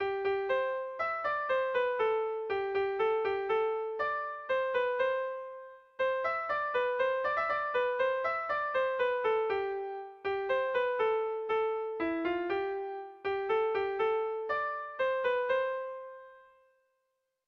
Bertso melodies - View details   To know more about this section
Kontakizunezkoa
ABDE